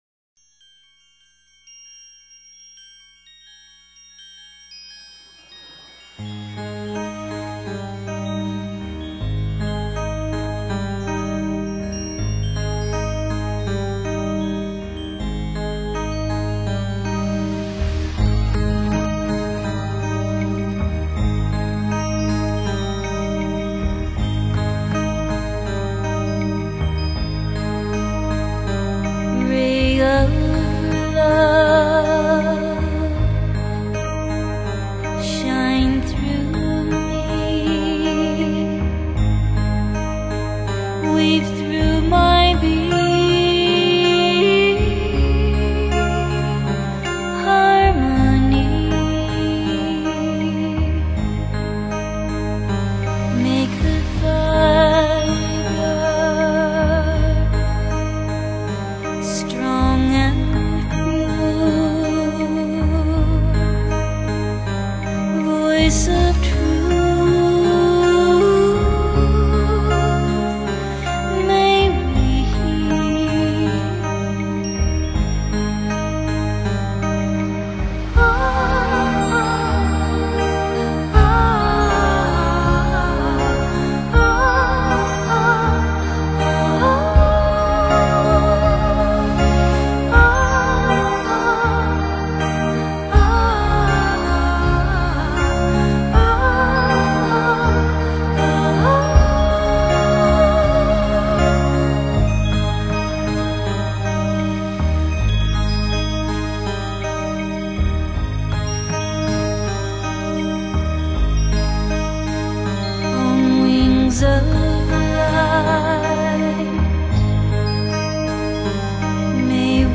新世纪音乐
总而言之，整张专辑混合了九个不同语系国家的音乐文化，曲风涵盖了世界 音乐、爵士、民谣。